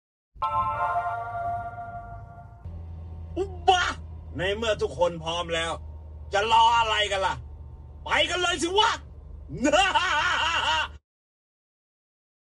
ไฟล์เสียงต้อนรับสำหรับ Apple CarPlay ที่ใช้เสียงของน้าค่อม
welcome-sound-file-for-apple-carplay-using-uncle-kom-chauncheun-voice-th-www_tiengdong_com.mp3